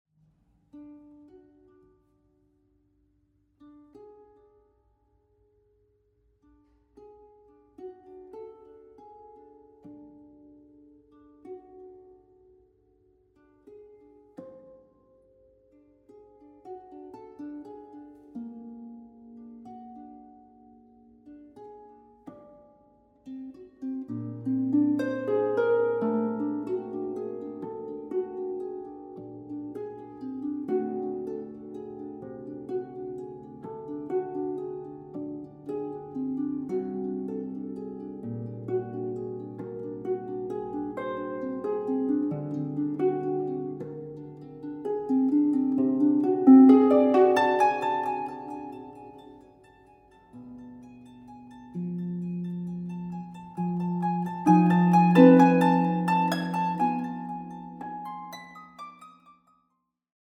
Zeitgenössische Musik für Harfe